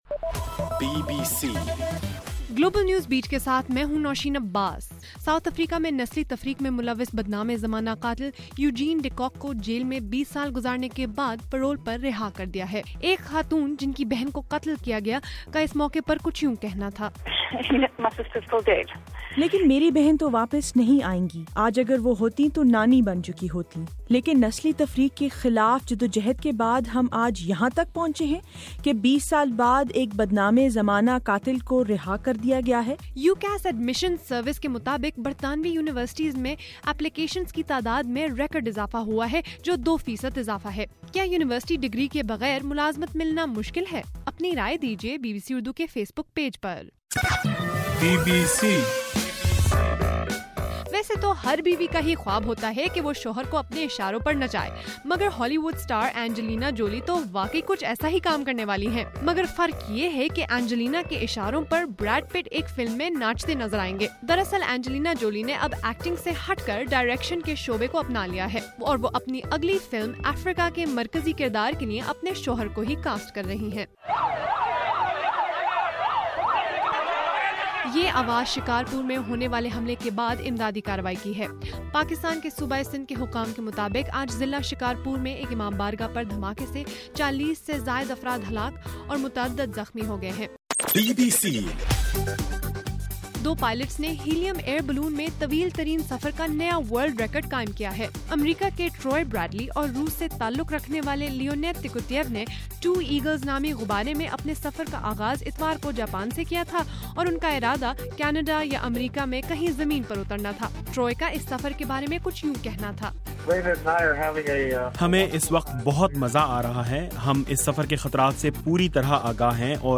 جنوری 30: رات 9 بجے کا گلوبل نیوز بیٹ بُلیٹن